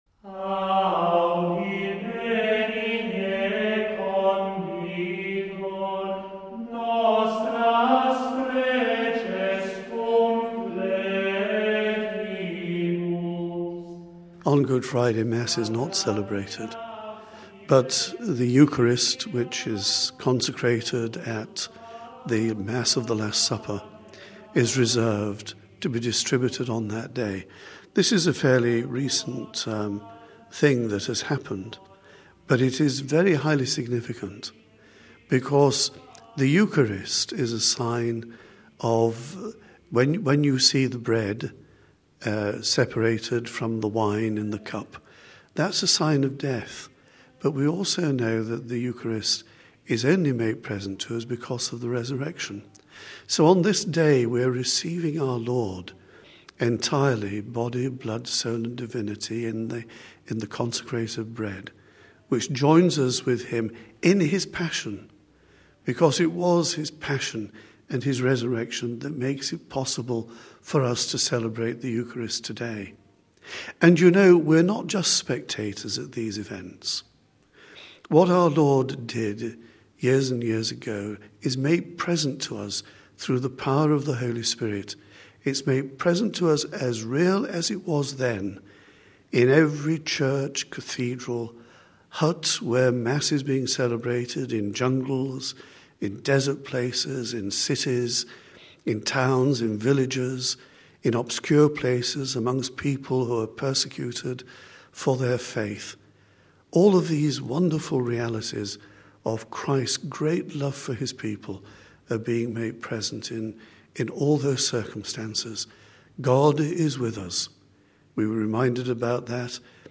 (Vatican Radio) In a series of reflections, the Secretary of the Vatican Congregation for Divine Worship and Discipline of the Sacraments, Archbishop Arthur Roche, walks Vatican Radio through the Holy Week liturgies, explaining their significance, symbolism and place within the history of Christ’s Passion, Death and Resurrection.